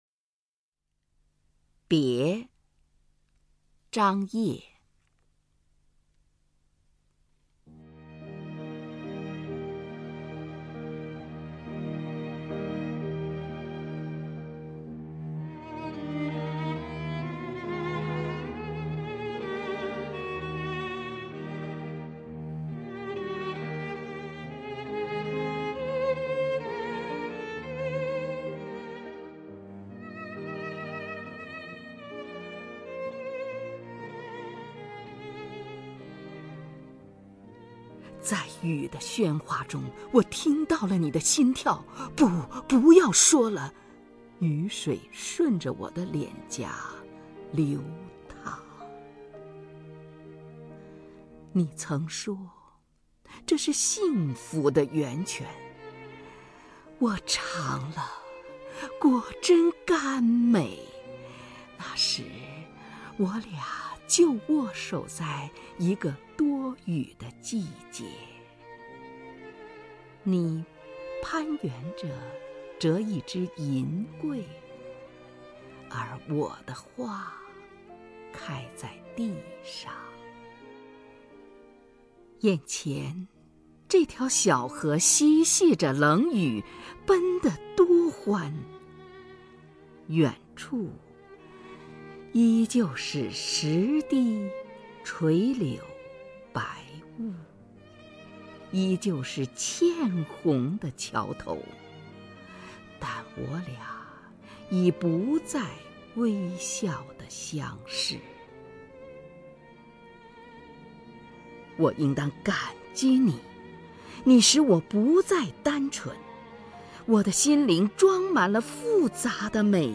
首页 视听 名家朗诵欣赏 虹云
虹云朗诵：《别》(张烨)